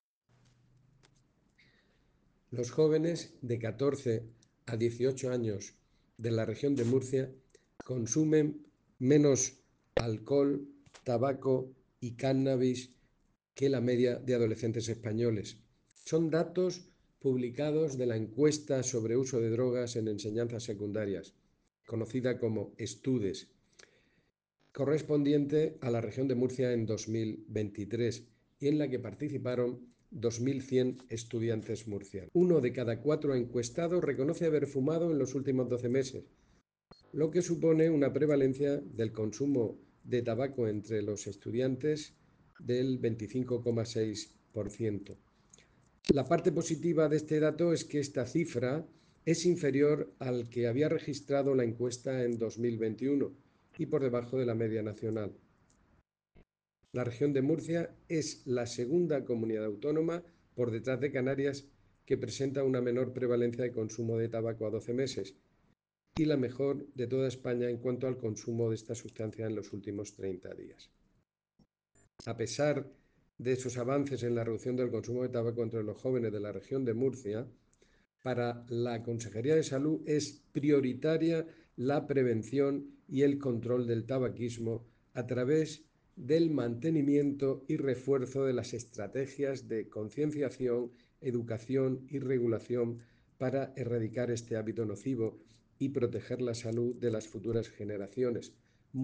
Sonido/ Declaraciones del director general de Salud Pública y Adicciones, José Jesús Guillén, sobre los resultados de la Encuesta sobre uso de drogas en Enseñanzas Secundarias (Estudes).